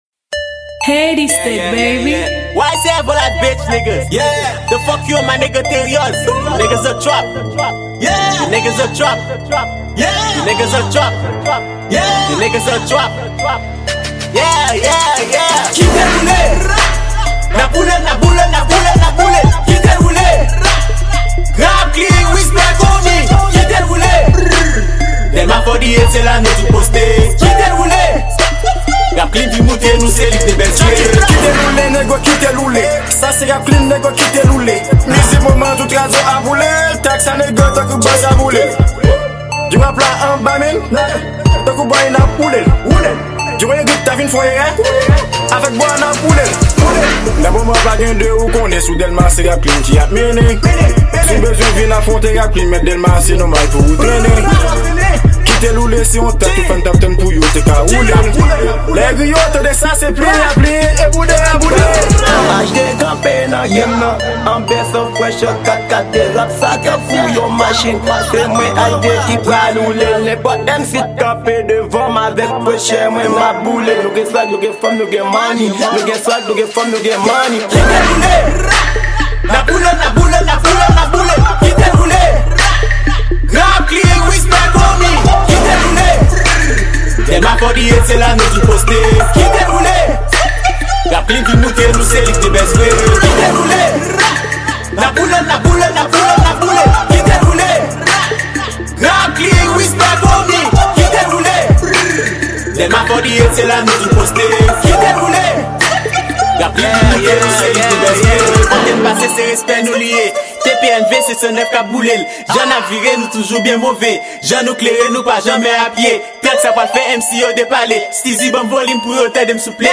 Genre : trap